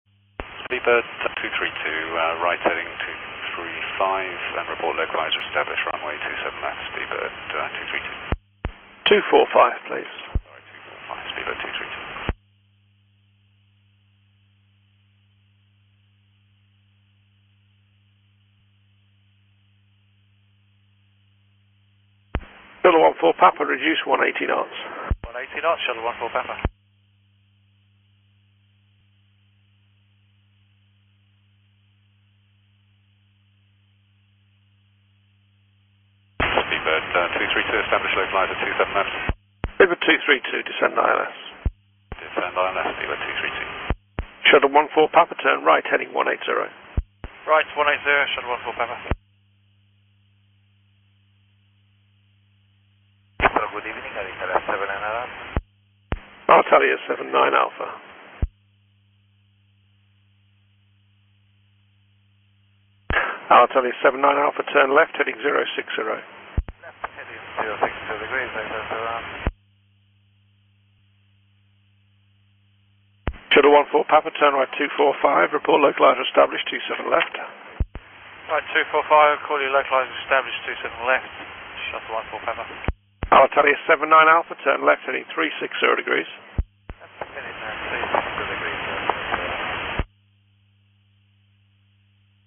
Suara Kokpit Pesawat
Kategori: Suara manusia
Keterangan: Rasakan pengalaman seru dari sudut pandang kokpit pilot saat berkomunikasi dengan Air Traffic Controller. Dengarkan percakapan radio yang menenangkan sambil menikmati suasana khas penerbangan yang menghadirkan sensasi berada langsung di dalam kokpit.
suara-kokpit-pesawat-id-www_tiengdong_com.mp3